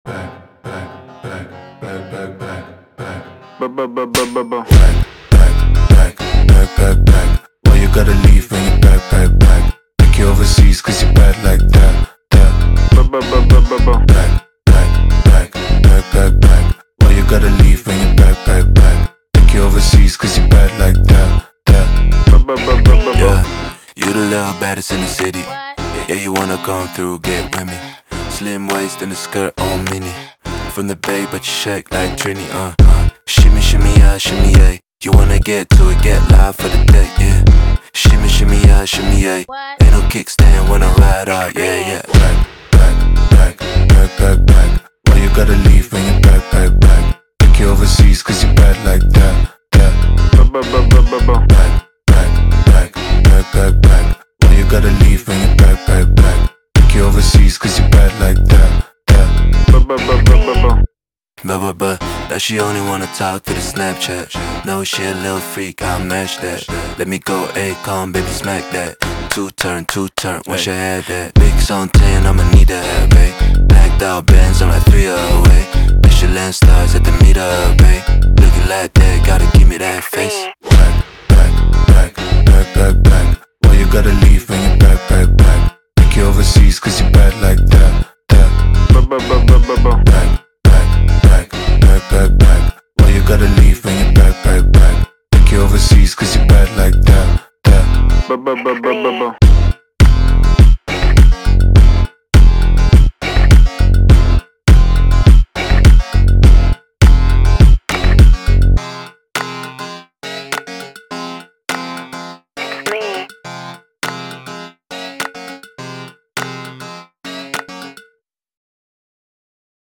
Трек размещён в разделе Зарубежная музыка / Рэп и хип-хоп.